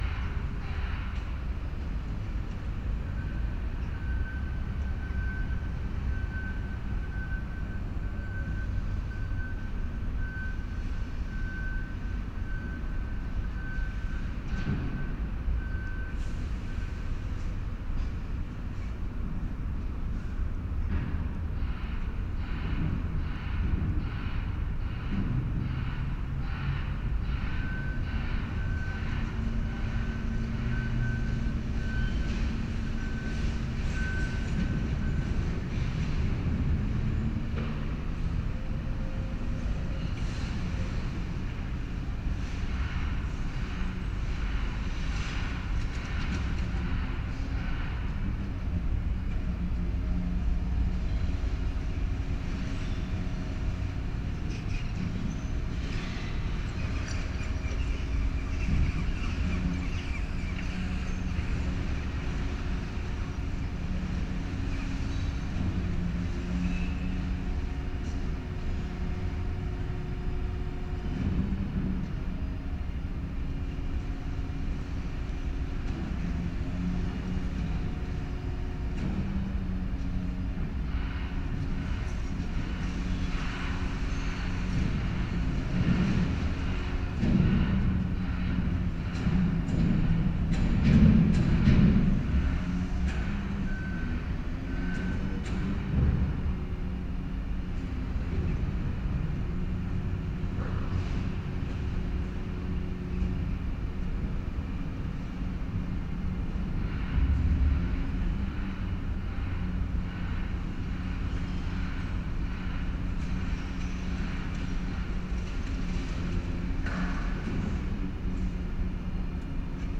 renewi_harbour_17.38.mp3